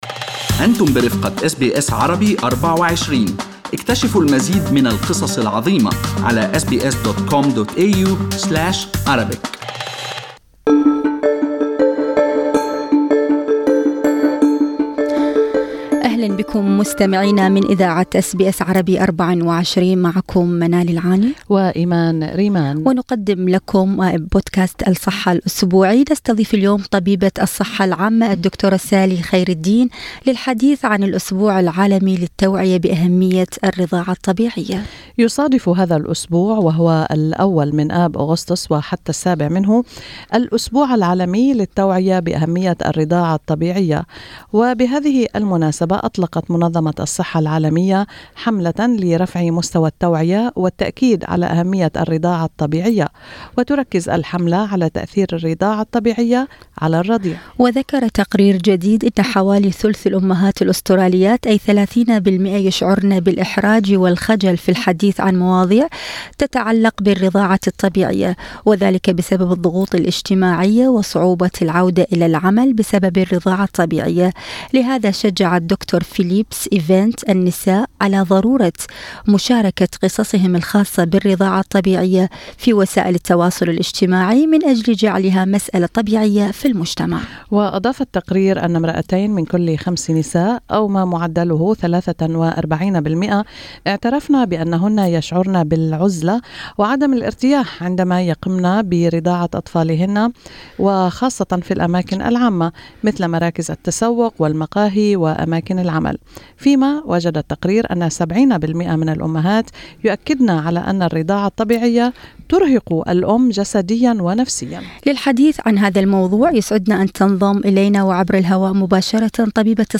أس بي أس عربي